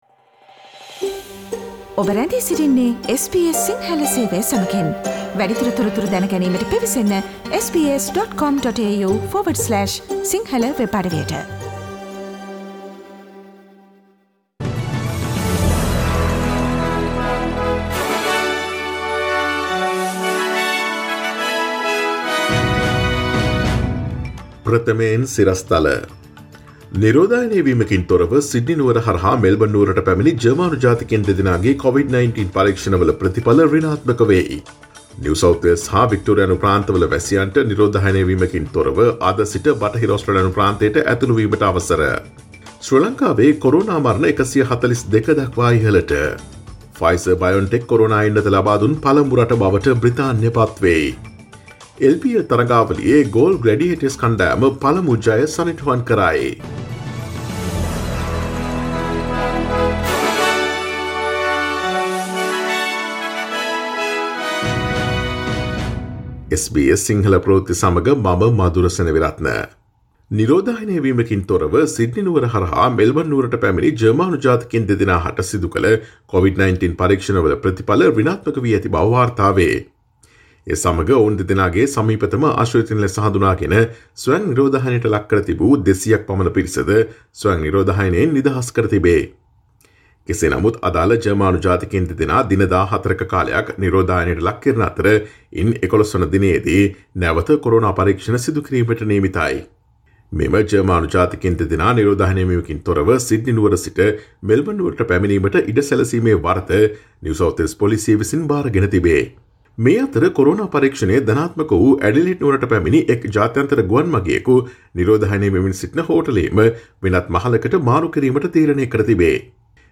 Today’s news bulletin of SBS Sinhala radio – Tuesday 8 December 2020